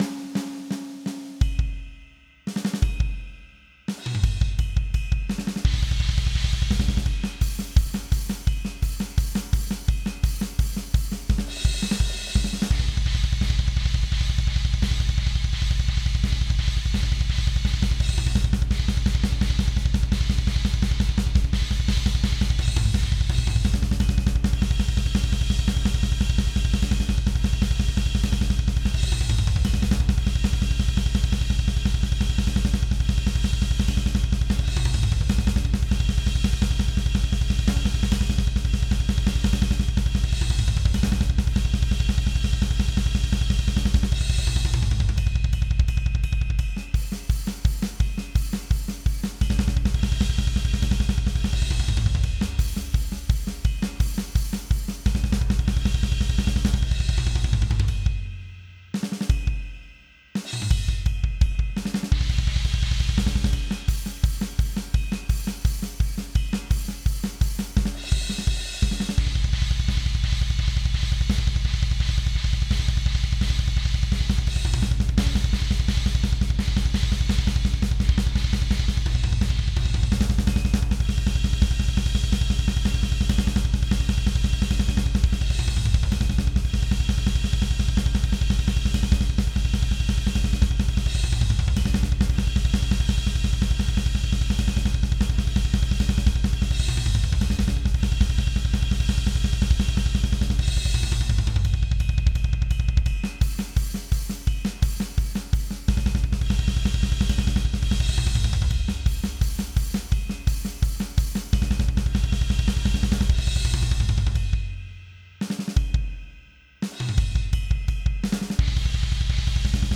Z.B. habe ich diese verstaubte Schlagzeugspur beim durchstöbern gefunden (Aber gerne natürlich auch was besseres zuschicken) Anhänge s1_drums.wav s1_drums.wav 37,7 MB